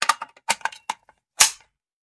Звуки автомата Калашникова
Звук сборки автомата Калашникова в деталях